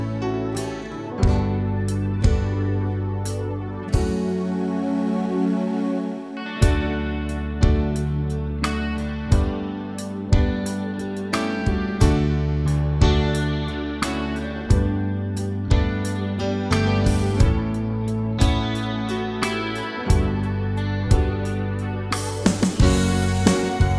No Girl Rock 6:13 Buy £1.50